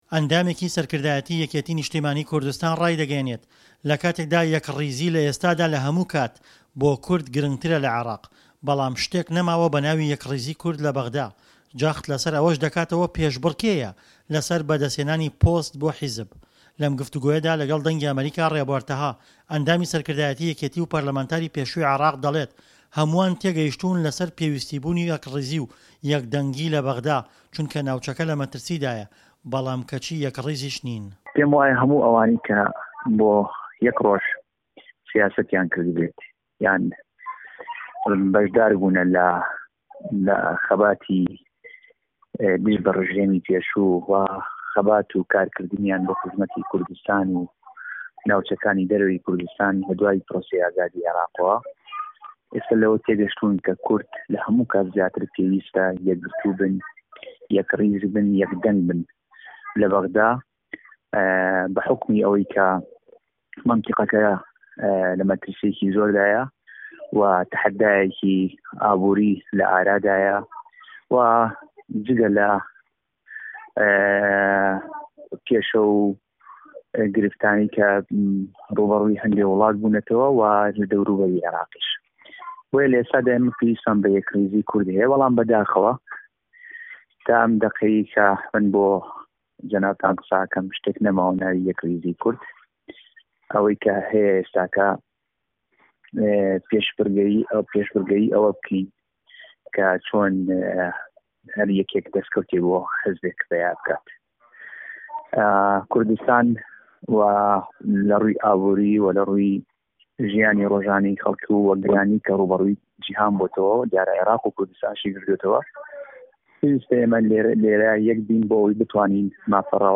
ئەندامێکی سەرکردایەتی یەکێتی نیشتیمانی کوردستان ڕایدەگەیەنێت، لە کاتێکدا یەکڕیزی لە ئێستادا لە هەمووکات بۆ کورد گرنگترە لە عێراق، بەڵام شتێک نەماوە بەناوی یەکڕیزی کورد لە بەغدا، جەخت لەسەر ئەوەشدەکاتەوە پێشبڕکێیە لەسەر بەدەستهێنانی پۆست بۆ حیزب. لەم گفتووگۆیەدا لەگەڵ دەنگی ئەمەریکا
ڕاپۆرتی